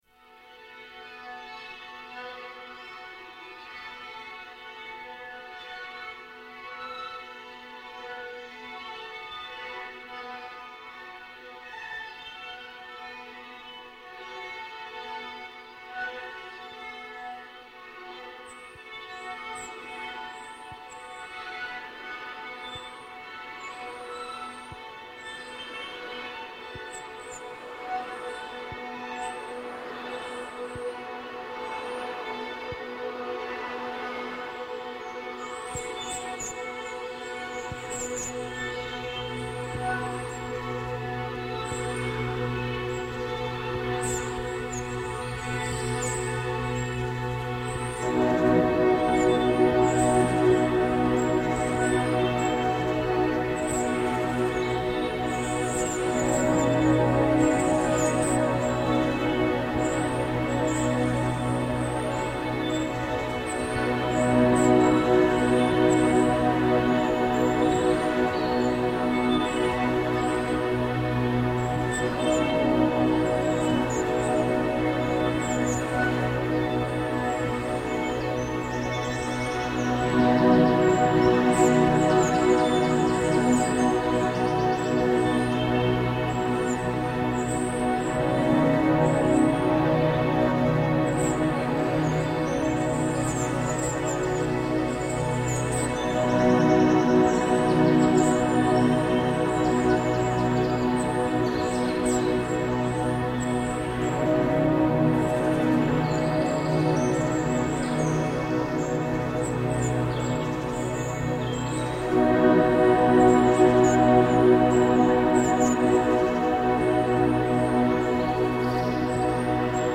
Electromagnetic dawn chorus reimagined by Cities and Memory.